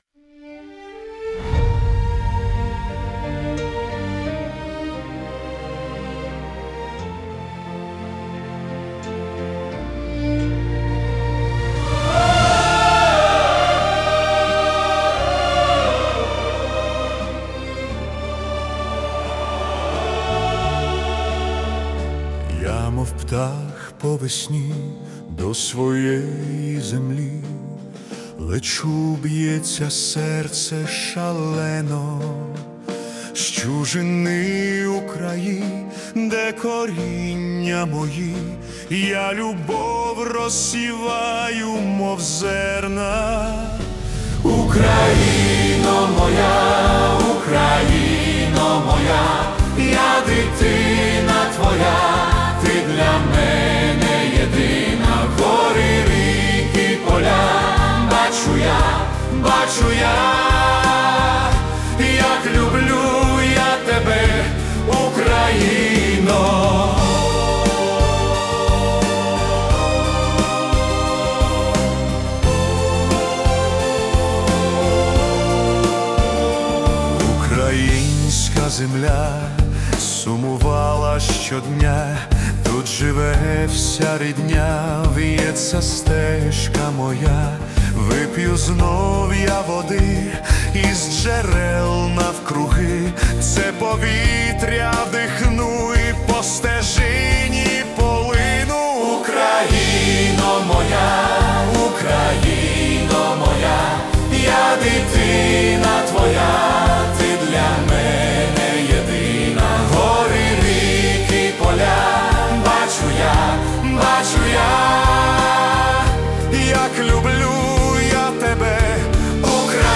🎵 Жанр: Orchestral Pop / Anthem